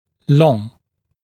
[lɔŋ][лон]длинный; длительный, протяженный